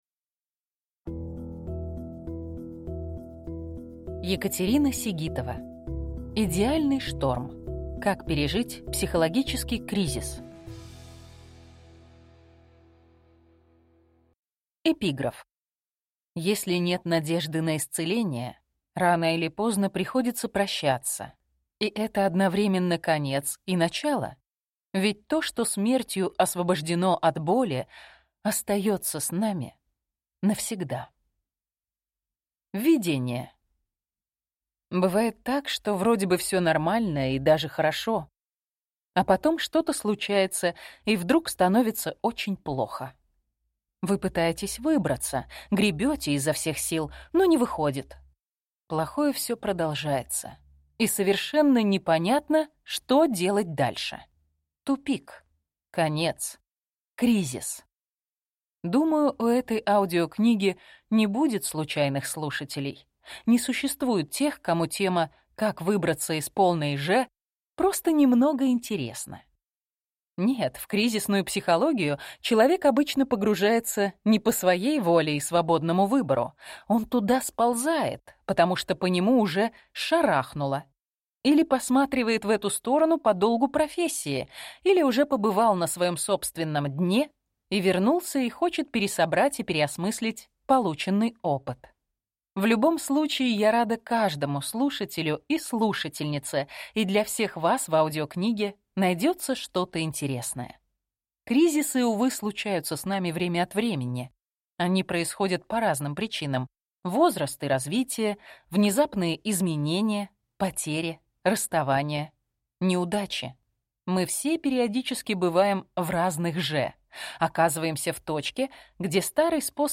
Аудиокнига Идеальный шторм. Как пережить психологический кризис | Библиотека аудиокниг